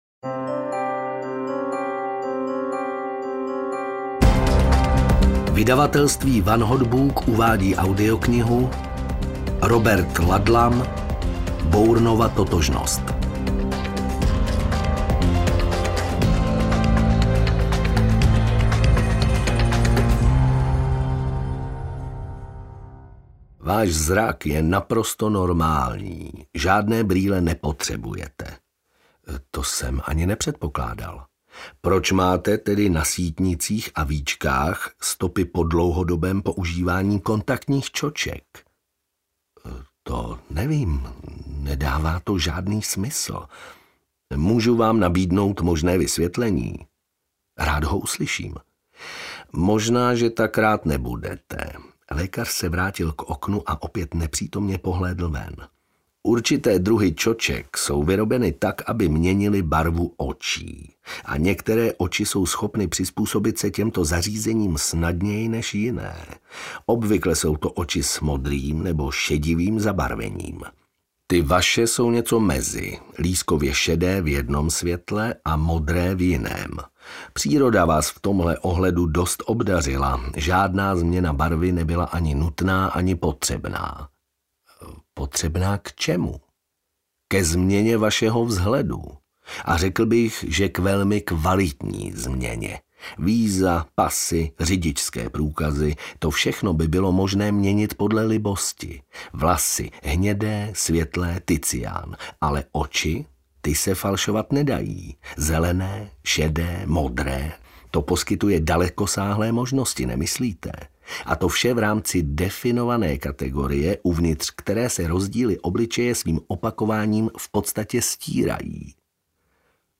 Bourneova totožnost audiokniha
Ukázka z knihy